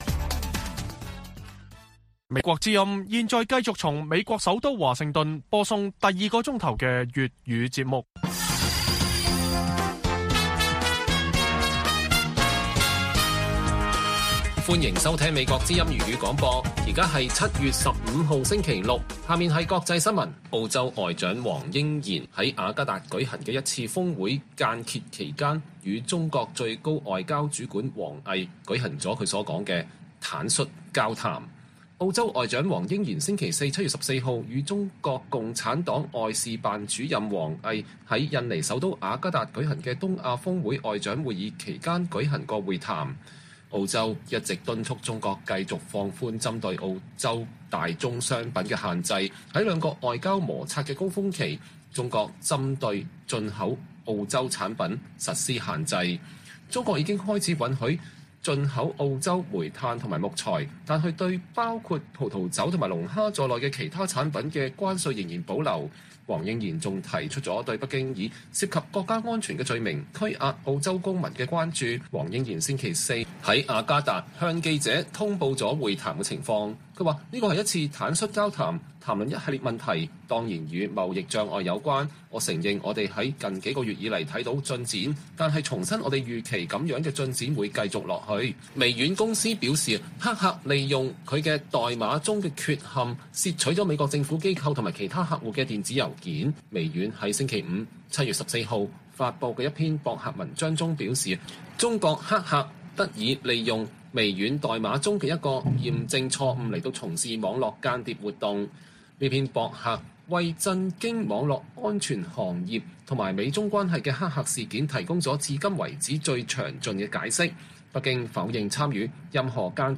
粵語新聞 晚上10-11點 ：德國首次公佈中國戰略，去風險降依賴為主要目標